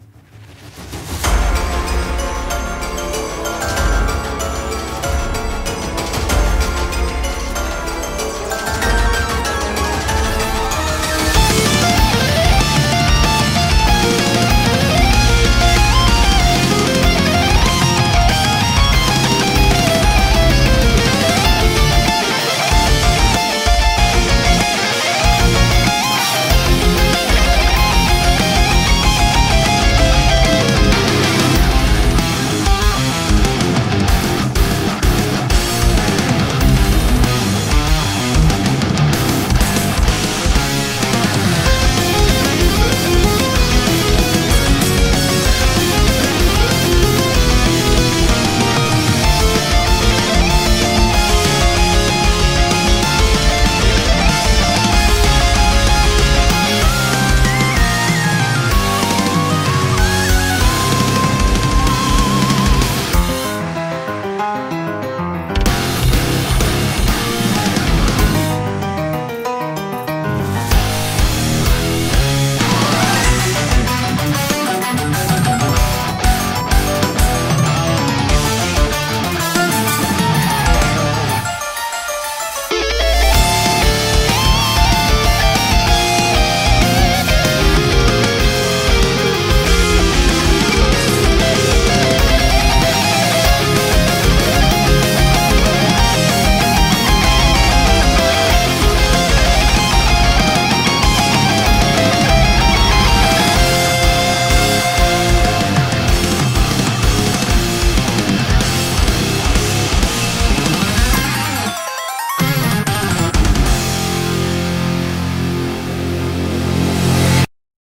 BPM190
Audio QualityPerfect (High Quality)
Comments[GOTHIC METAL]